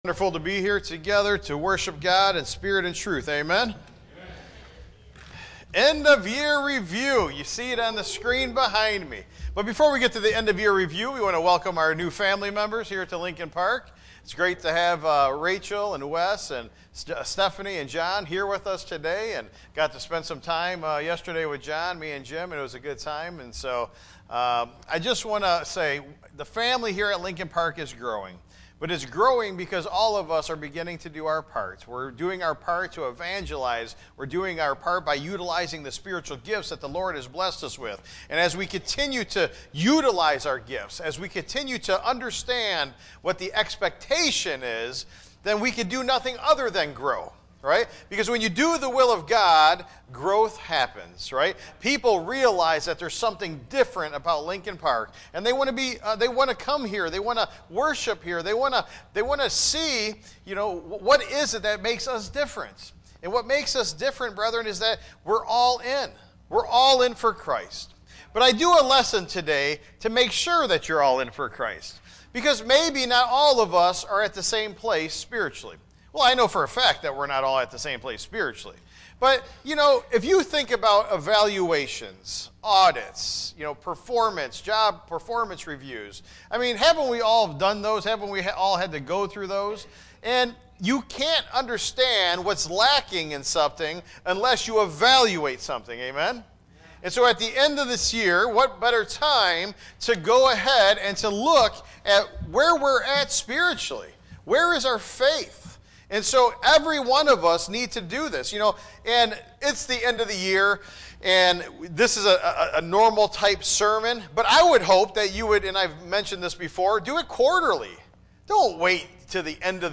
Tagged with sermon Audio (MP3) 13 MB Previous A Higher Calling Next Revelation 8-9 Seals and Symbols